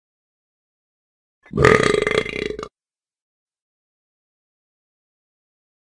Burp Sound
human
Burp